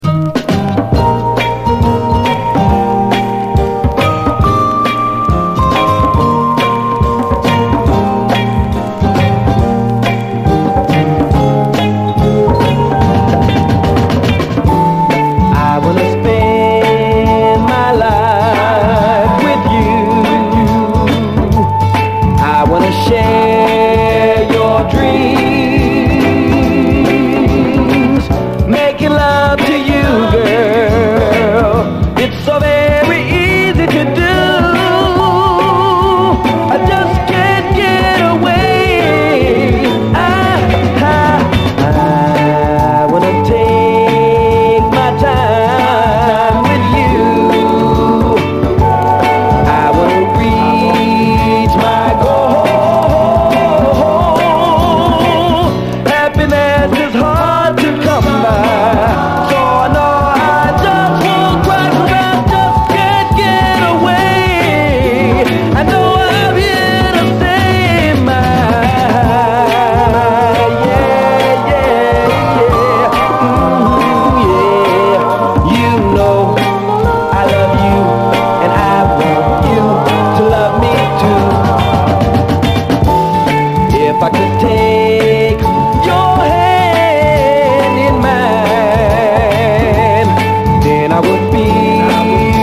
SOUL, 70's～ SOUL, 7INCH
フィンガースナッピンなドリーミー・クロスオーヴァー・ソウル！
美メロ・スウィート・クロスオーヴァー・ソウル！シャッフルするリズムのフィンガースナッピンなドリーミー・ソウル！